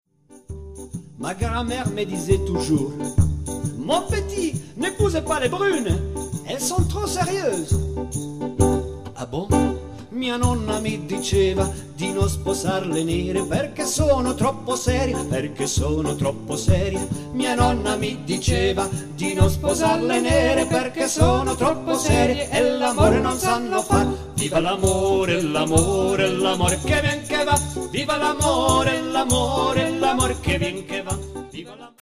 extraits live du spectacle en solo